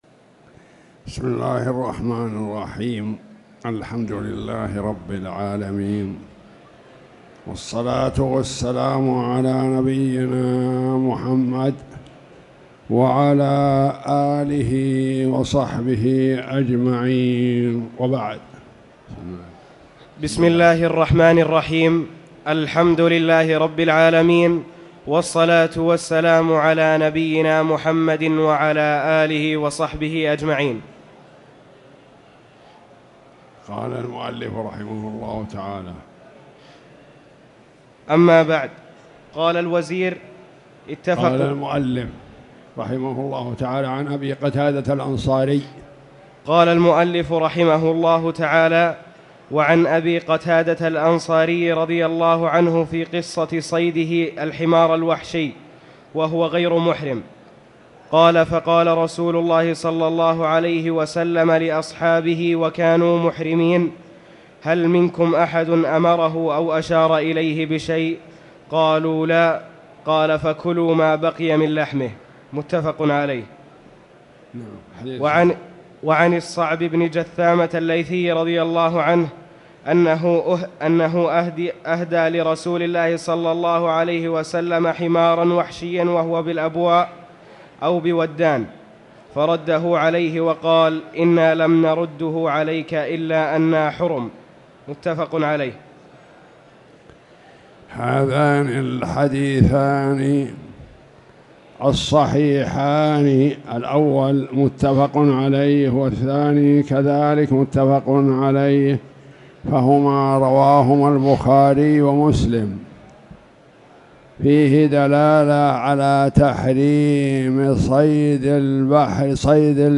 تاريخ النشر ٦ جمادى الآخرة ١٤٣٨ هـ المكان: المسجد الحرام الشيخ